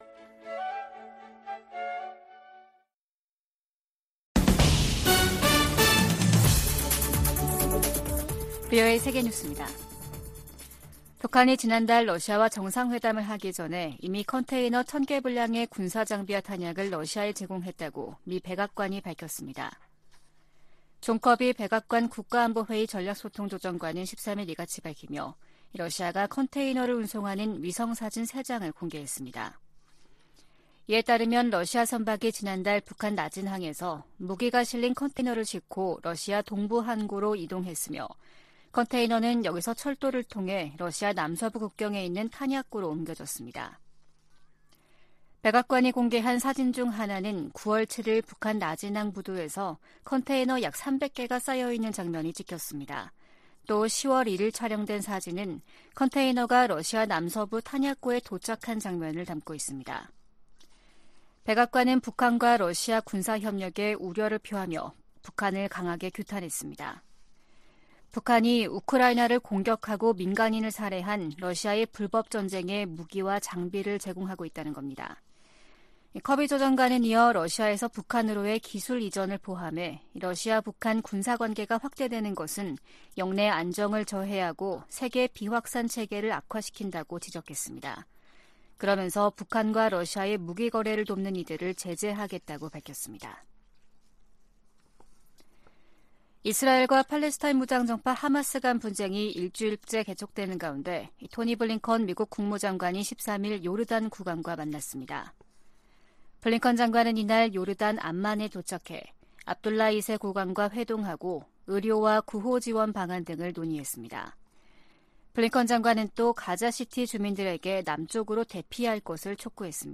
VOA 한국어 아침 뉴스 프로그램 '워싱턴 뉴스 광장' 2023년 10월 14일 방송입니다. 이스라엘과 하마스의 전쟁 등 중동발 위기가 한반도에 대한 미국의 안보 보장에 영향을 주지 않을 것이라고 백악관이 밝혔습니다. 북한이 하마스처럼 한국을 겨냥해 기습공격을 감행하면 한미연합사령부가 즉각 전면 반격에 나설 것이라고 미국 전문가들이 전망했습니다. 중국 내 많은 북한 주민이 송환된 것으로 보인다고 한국 정부가 밝혔습니다.